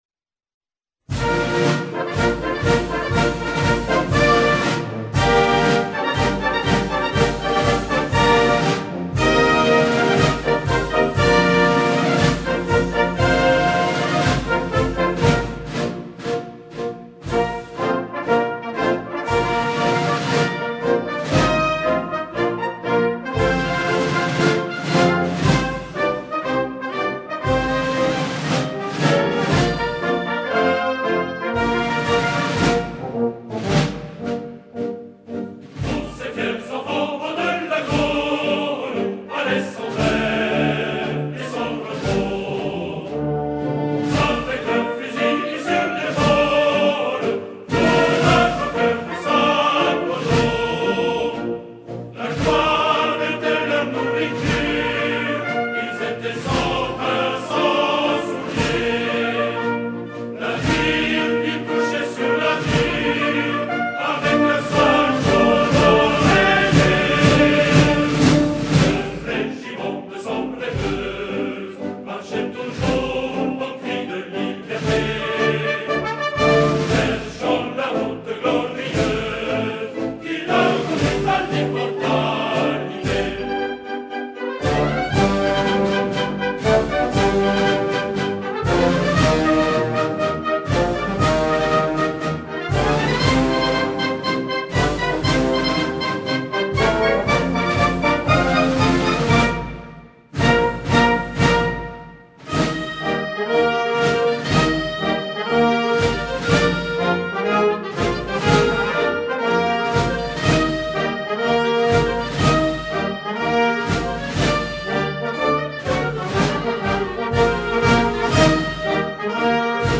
French march
French march ~1600 (Baroque, Classical) France Group: March The march tempo of 120 beats or steps per minute. French march has emphasis on percussion and brass, often incorporating bugle calls as part of the melody or as interludes between strains.